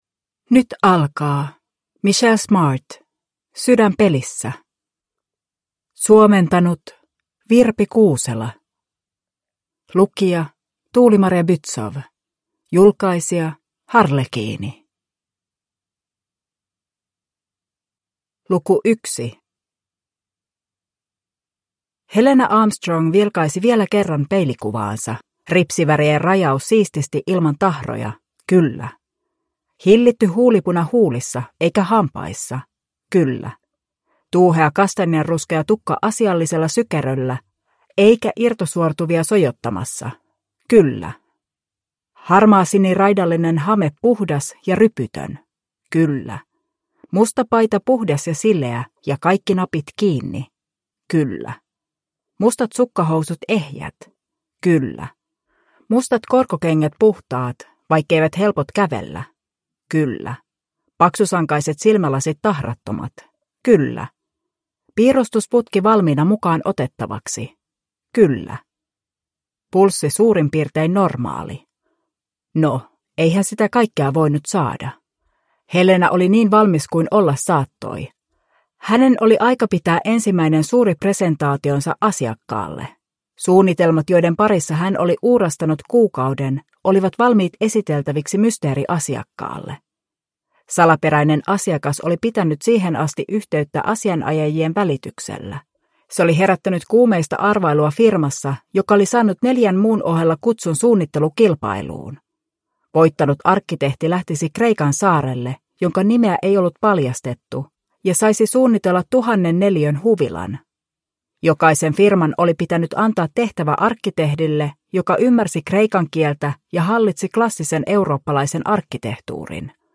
Sydän pelissä (ljudbok) av Michelle Smart